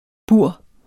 Udtale [ ˈbuɐ̯ˀ ]